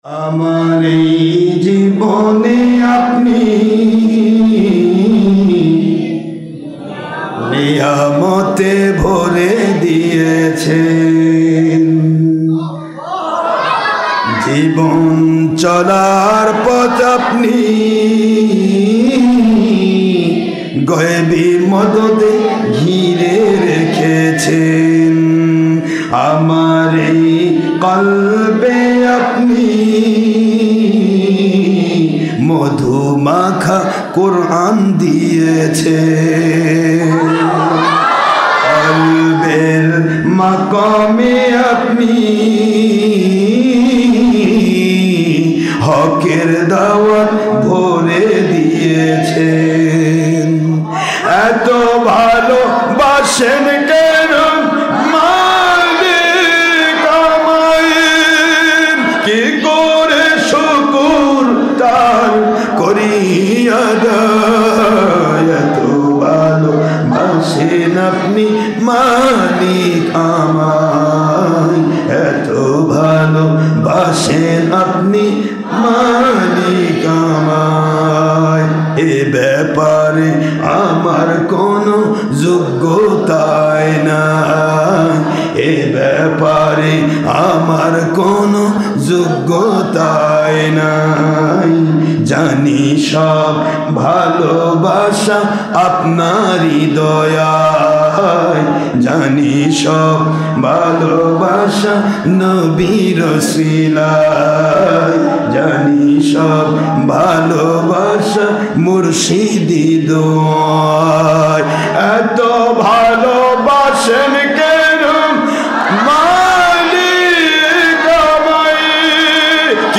কাসিদা